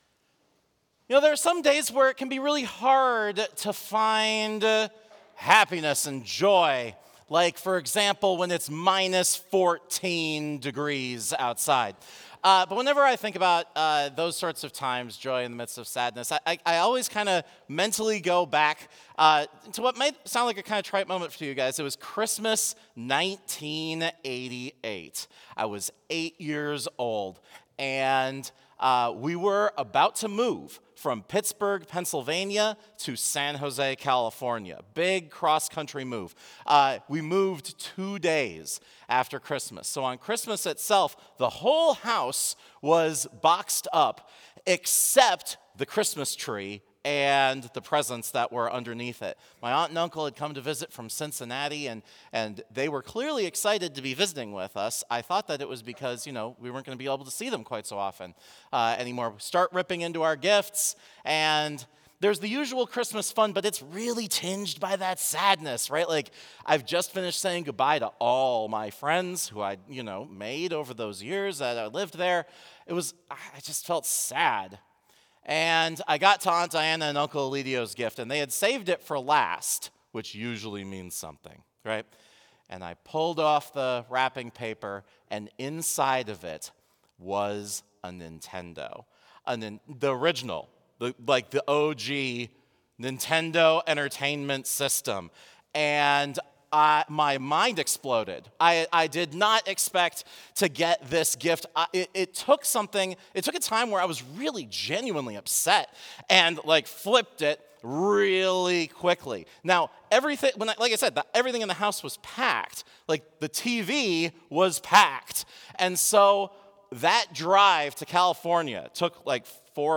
Complete service audio for Chapel - Tuesday, January 21, 2025